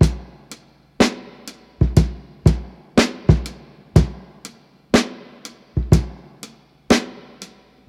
• 61 Bpm Rap Drum Loop Sample B Key.wav
Free breakbeat - kick tuned to the B note. Loudest frequency: 860Hz
61-bpm-rap-drum-loop-sample-b-key-8KI.wav